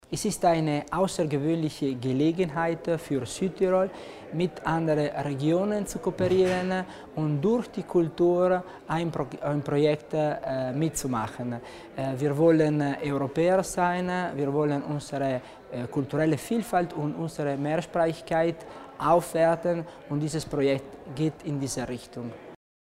Landesrat Tommasini zur Bedeutung des Abkommens